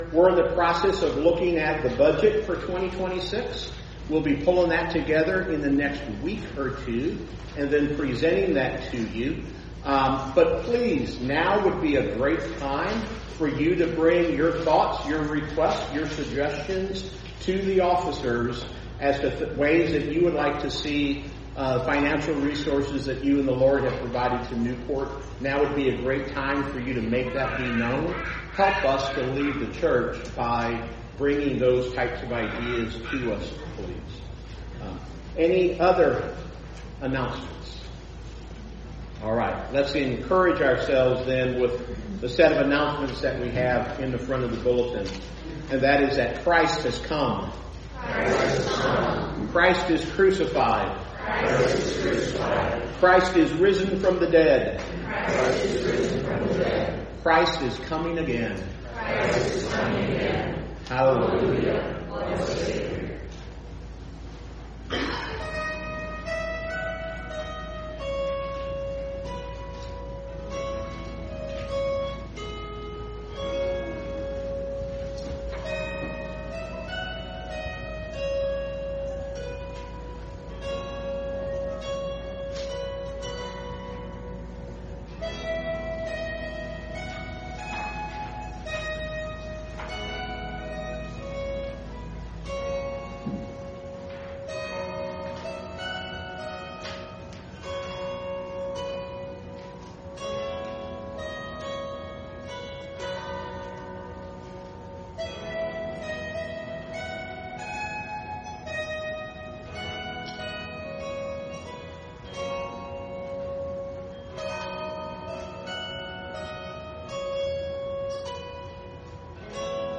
Worship Service
Congregation Singing
Voice and Guitar